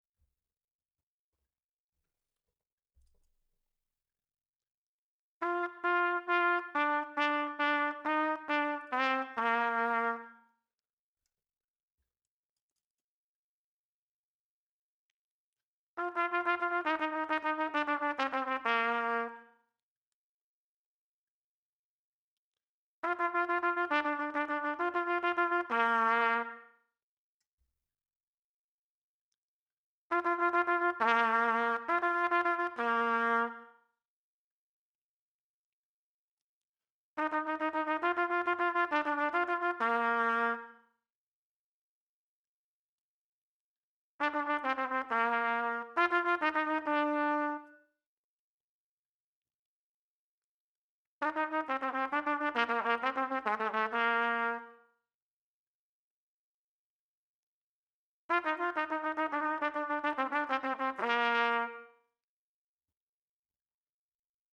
Triple Tonguing Exercises
The click track has been removed in level two so that let you may more easily check the evenness of your notes.